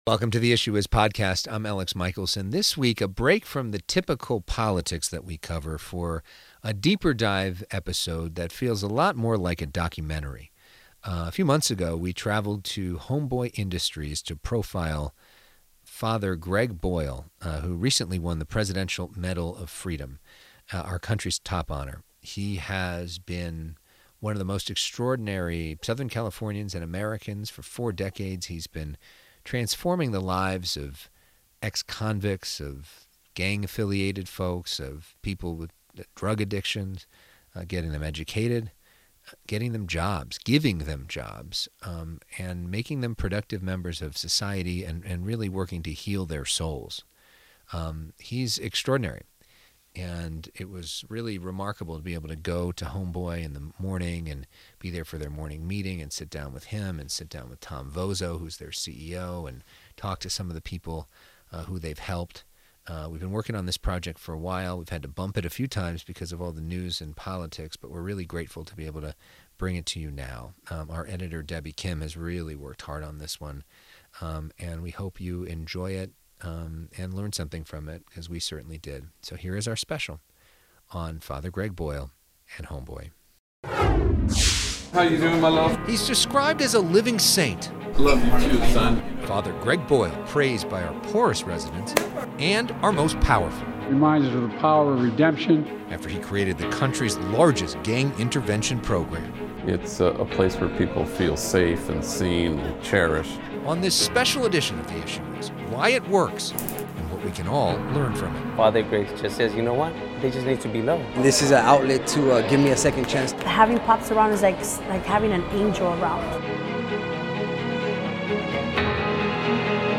This week, we travel to Homeboy Industries in Los Angeles for a special documentary focused on Father Greg Boyle.